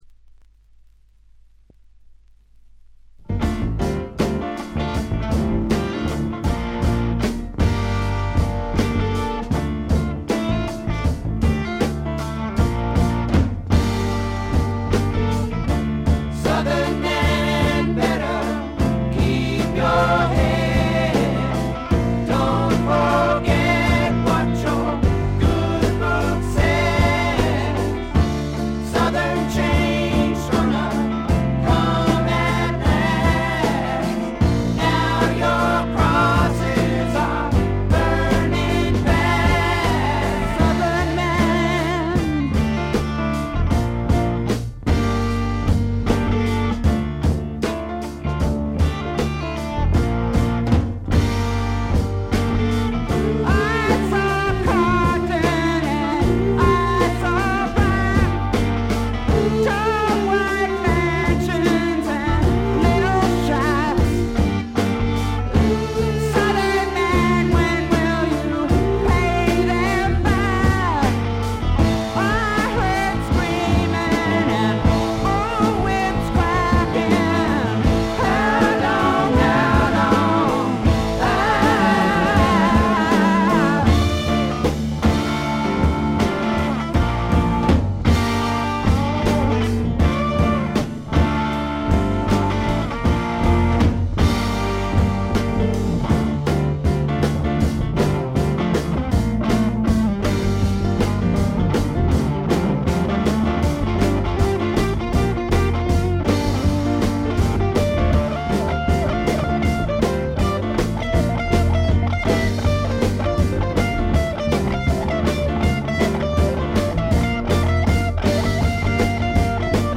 試聴曲は現品からの取り込み音源です。
guitar, vocal
bass
drums, vocal